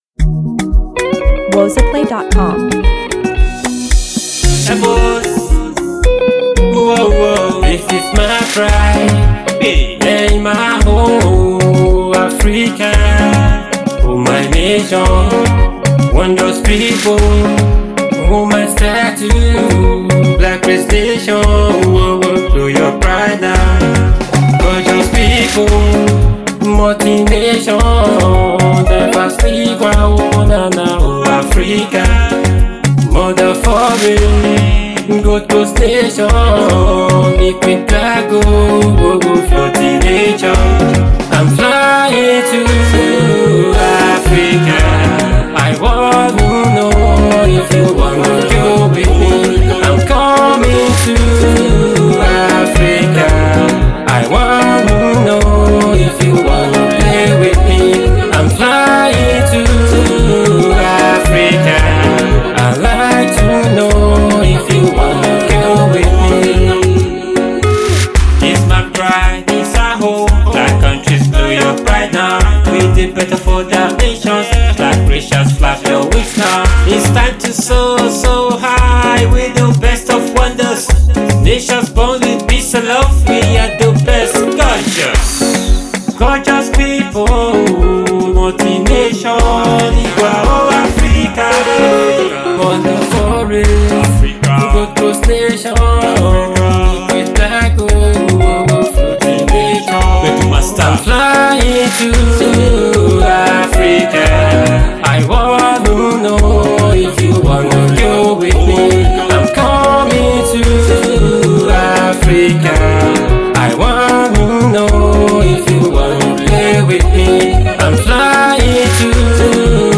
energetic Afropop song
afrobeats